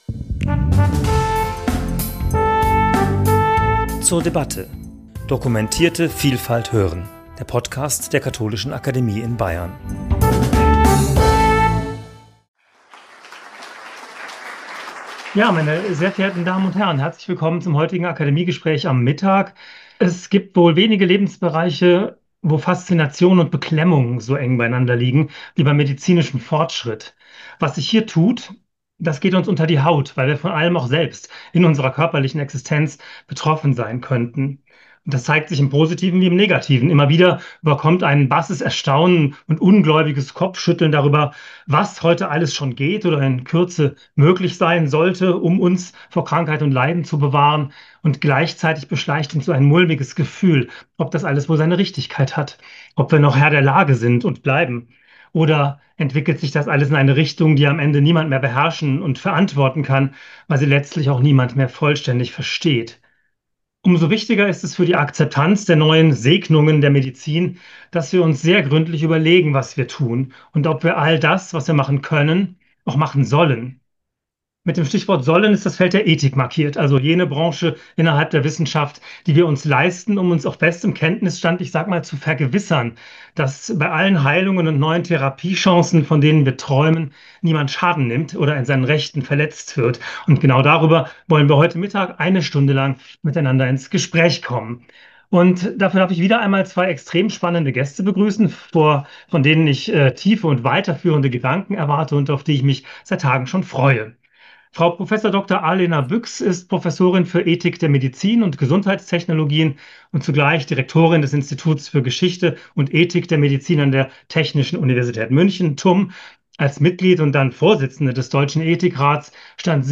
Gespräch zum Thema 'Rennt die medizinische Innovation der Ethik davon?'